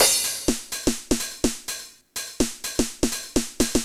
Index of /musicradar/retro-house-samples/Drum Loops
Beat 14 No Kick (125BPM).wav